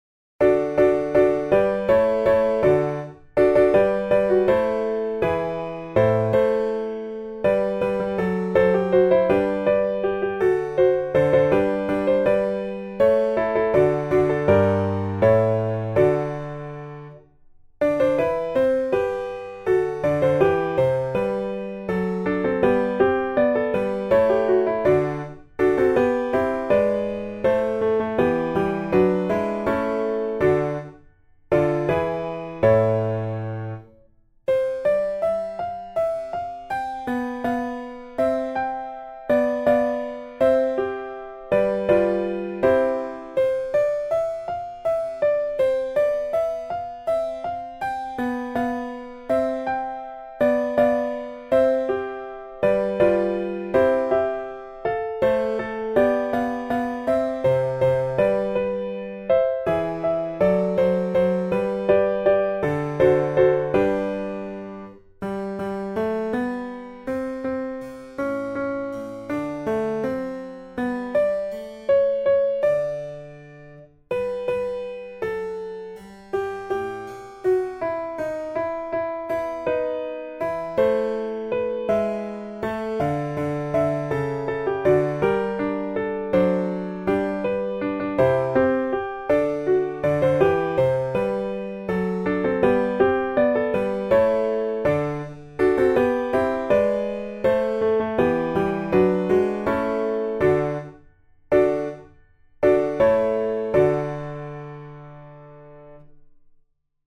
A 4 voces (Tiple I, II, Alto y Tenor)